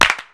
clap 01.wav